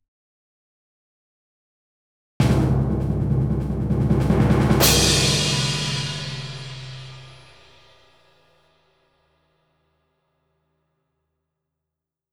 drum-roll-please.wav